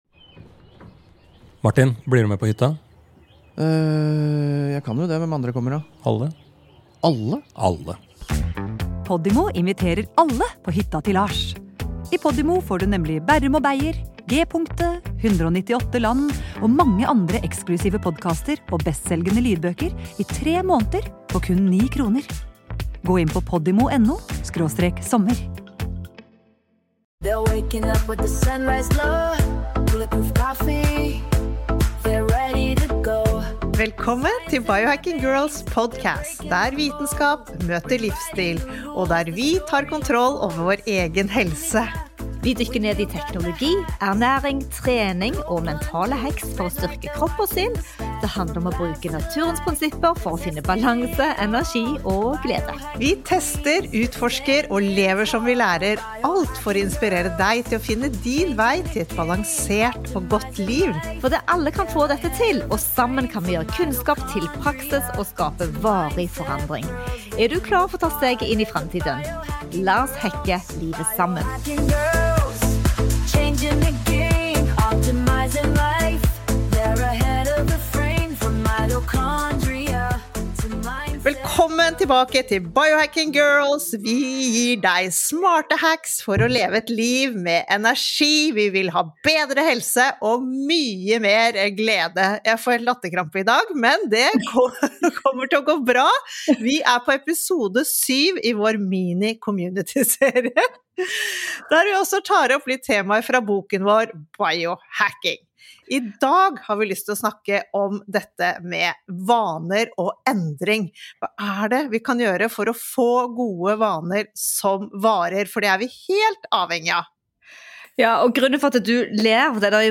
er med oss i studio for å diskutere den nye behandlingsfronten mot kreft.